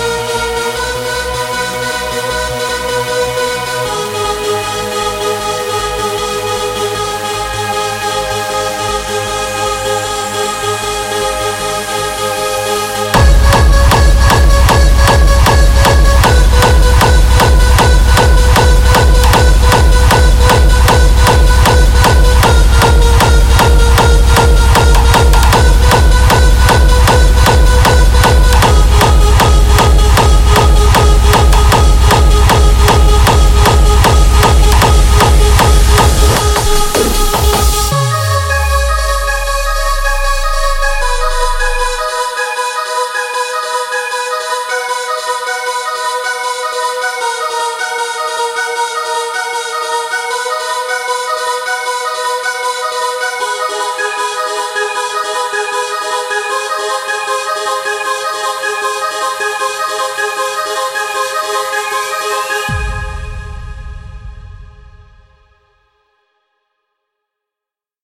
ravemix
harddance/hardstyle